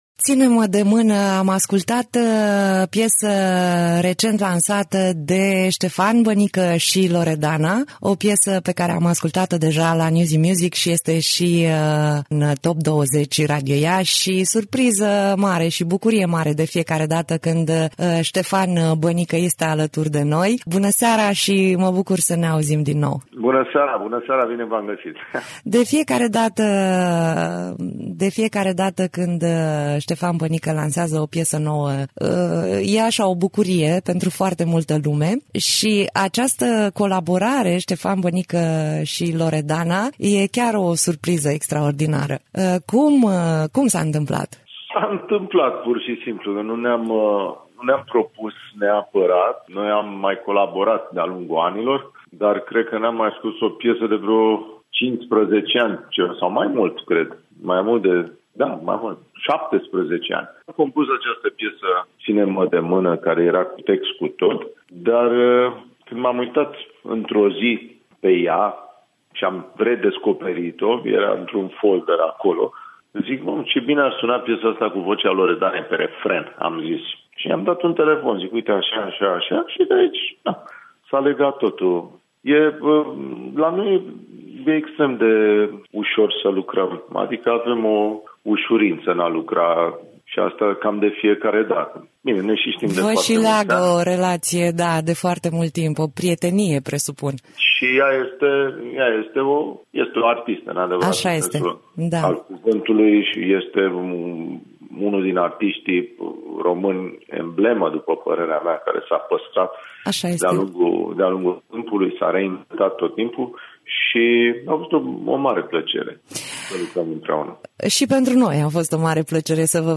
(INTERVIU) Ștefan Bănică